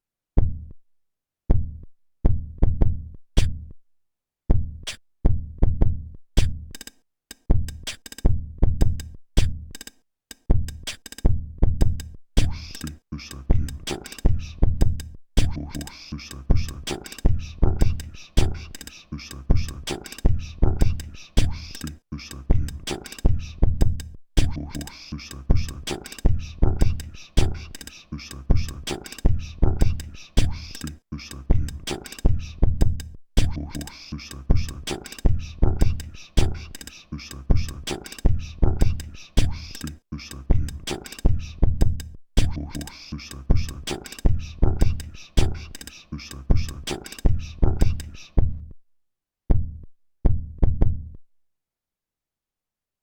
Made with PO-33 K.O!. All samples recorded with the built-in microphone.